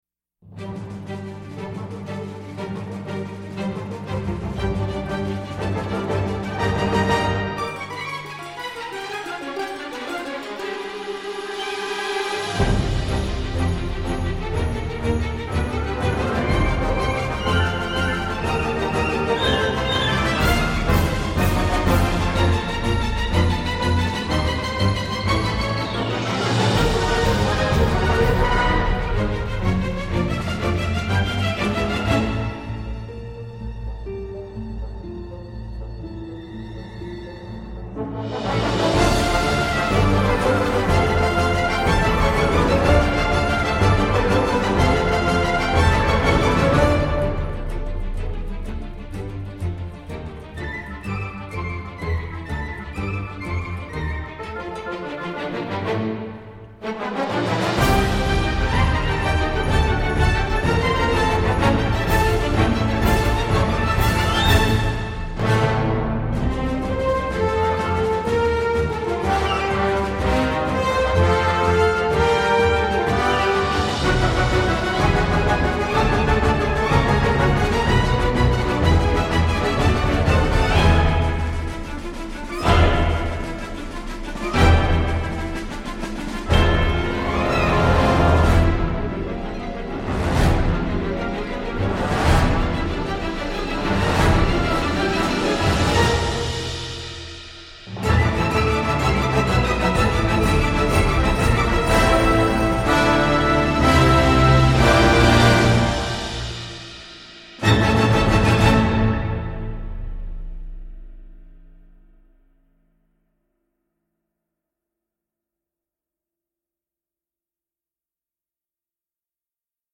Action débridée et émotion sont au rendez-vous.